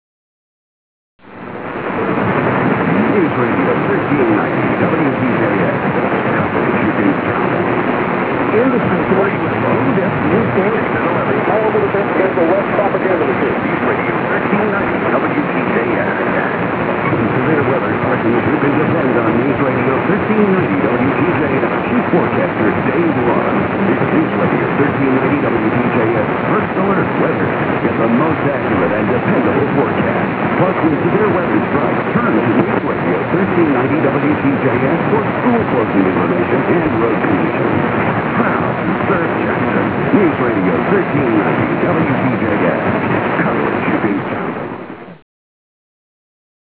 Auroras sometimes bring signals that are a bit hard to hear. I've heard weaker though!